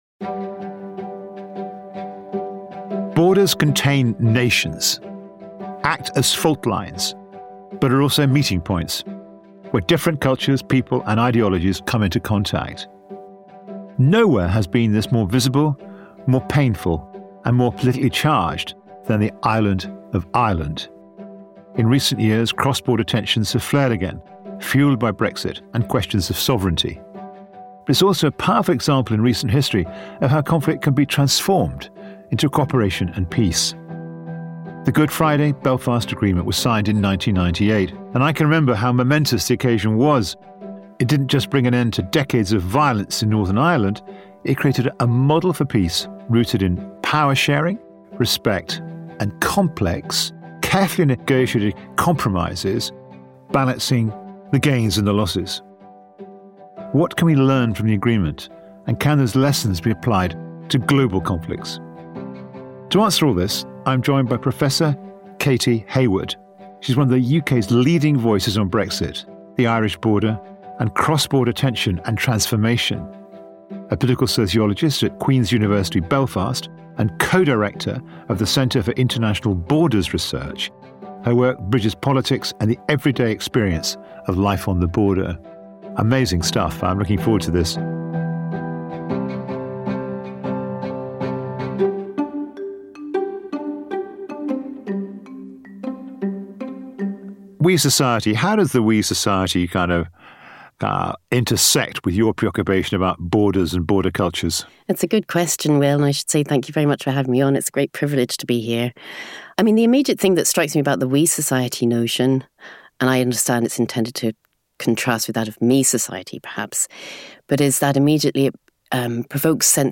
Join acclaimed journalist and Academy president Will Hutton, as he invites guests from the world of social science to explore the stories behind the news and hear their solutions to society’s most pressing problems.